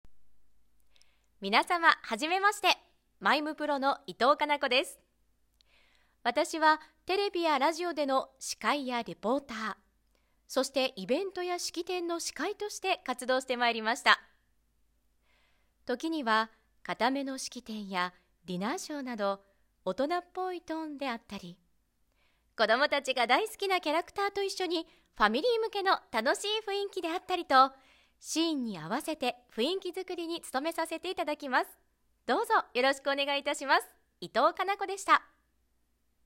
ボイスサンプル
自己紹介